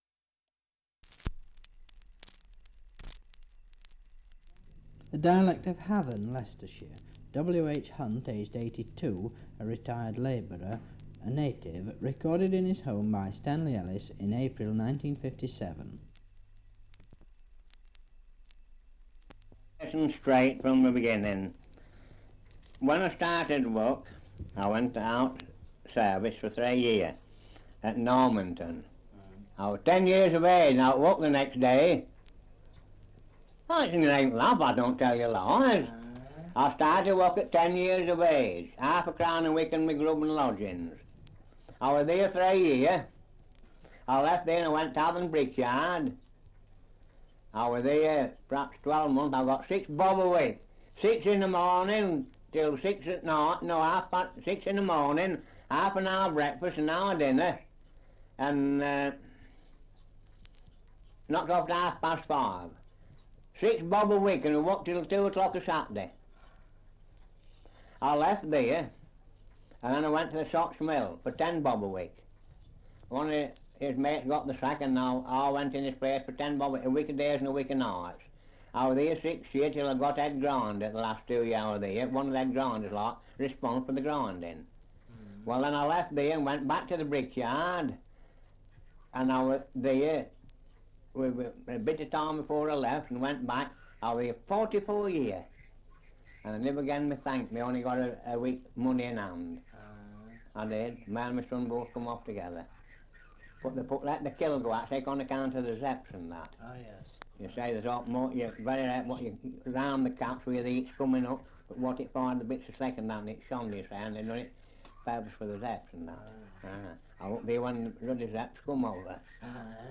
Survey of English Dialects recording in Hathern, Leicestershire
78 r.p.m., cellulose nitrate on aluminium